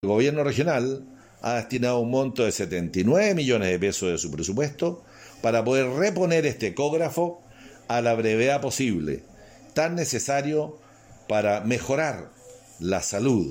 El Intendente Harry Jürgensen explicó que se destinarán 79 millones de pesos.